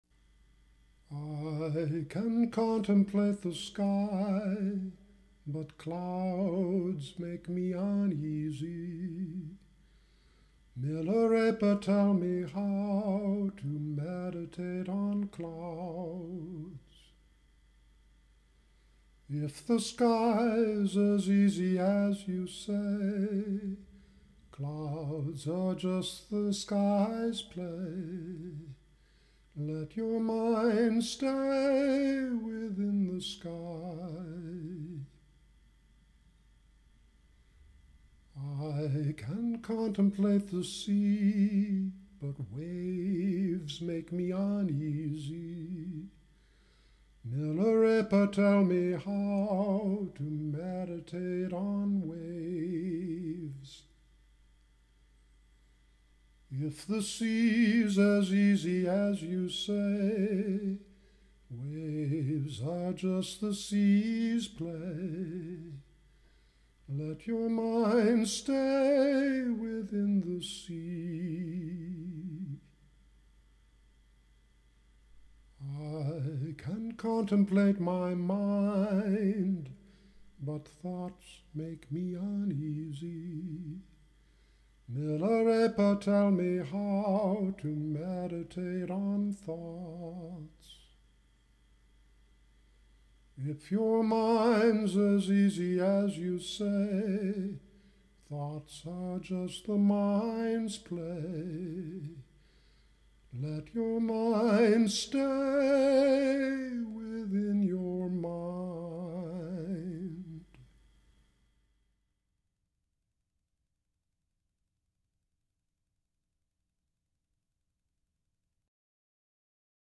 Song of Paldarbom - solo (Download 1.89 MB)
Dharma song based on the poetry of Milarepa (1052-1135)
Paldarbom_solo.mp3